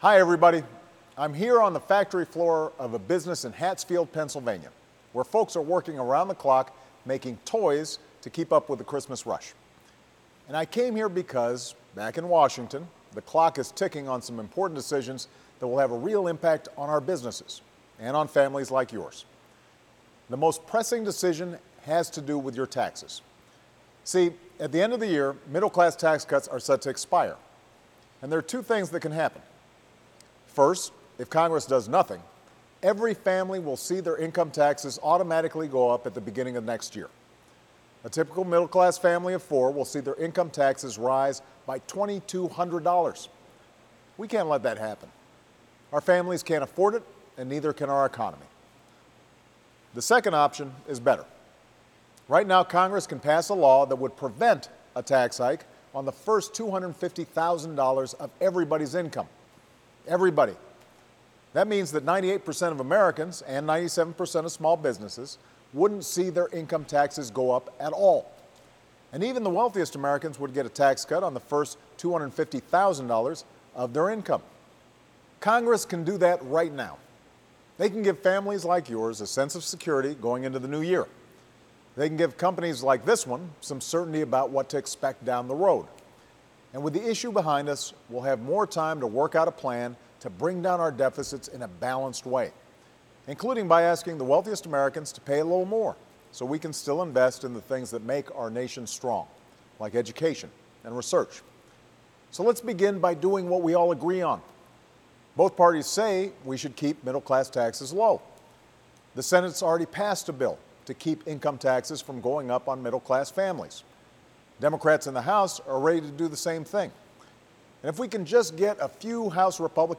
President Obama speaks to the American people from a busy factory floor in Pennsylvania about the urgent need to pass the middle class tax cuts, which will give families and businesses preparing for the holidays the certainty they need going into the New Year.